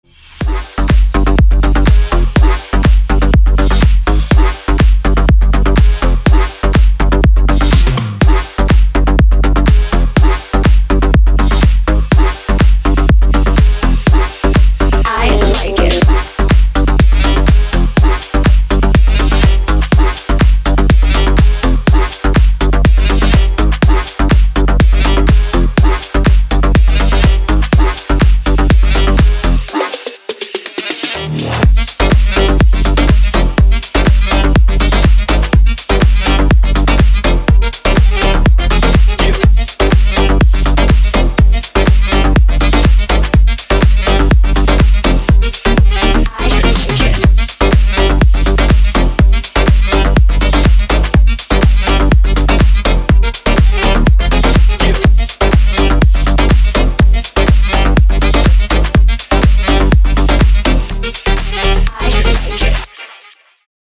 • Качество: 256, Stereo
громкие
deep house
женский голос
dance
Electronic
EDM
Club House
электронная музыка
G-House